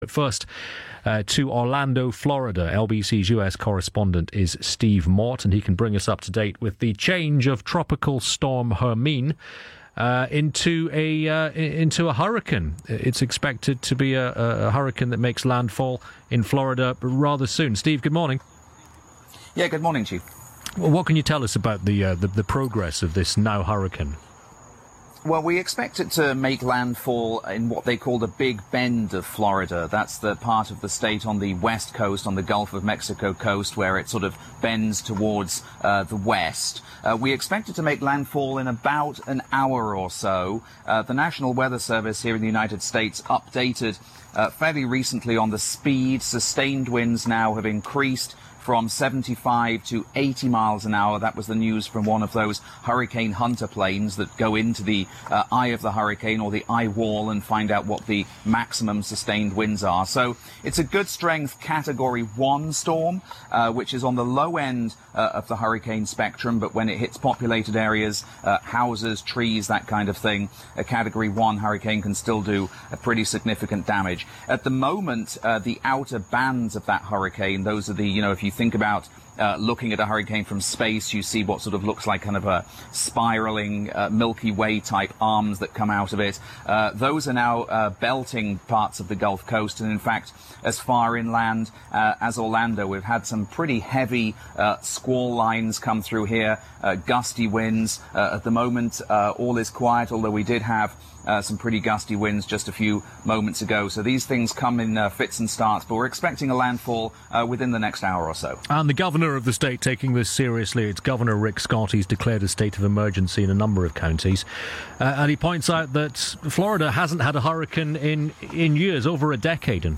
reporting from Orlando on LBC about the imminent arrival of Hurricane Hermine in Florida.